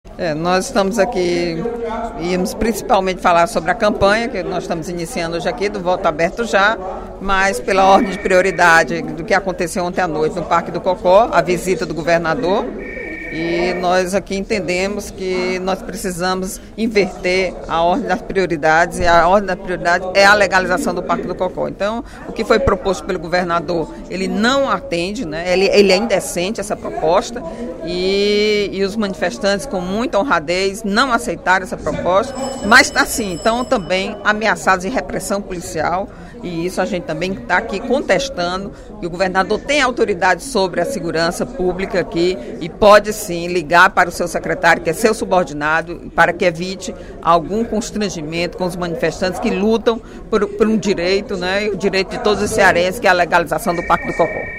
Durante o primeiro expediente da sessão plenária desta terça-feira (06/08), a deputada Eliane Novais (PSB) criticou a proposta feita pelo governador do Estado, Cid Gomes, ao movimento que ocupa o Parque do Cocó, de legalizar o parque em troca da construção dos viadutos na área.